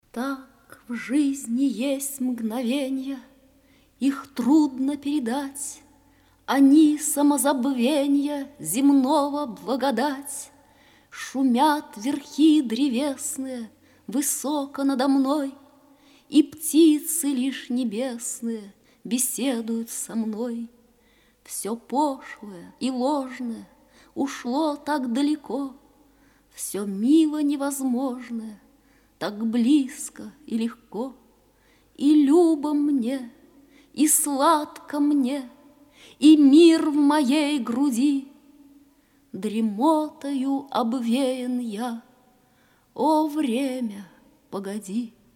1. «Фёдор Тютчев, читает Белла Ахмадулина – “Так, в жизни есть мгновения…”» /